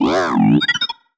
sci-fi_driod_robot_emote_24.wav